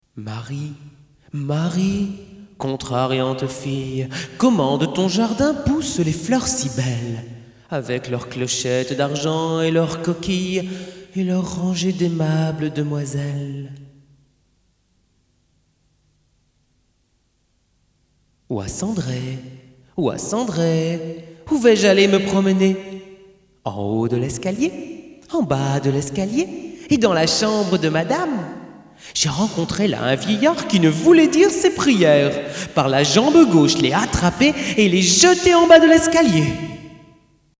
Comptine de ma mère l’oie « Marie » et « Oie cendrée »